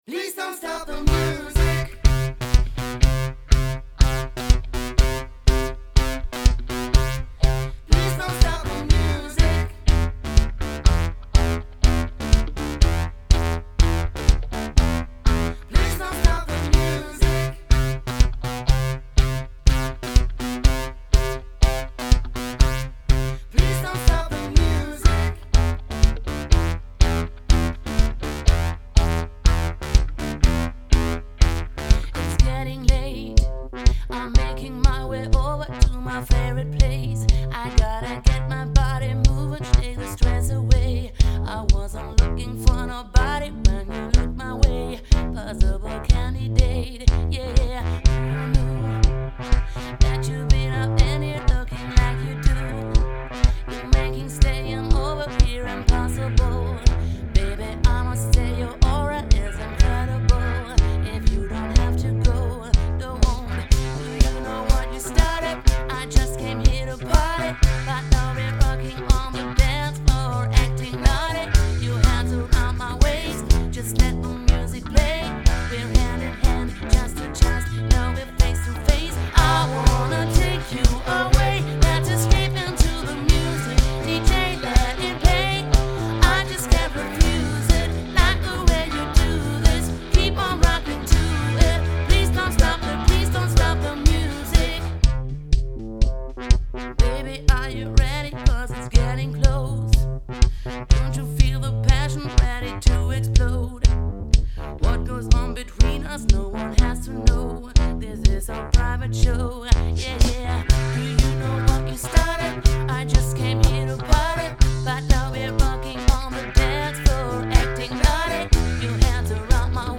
100 % Live Musik!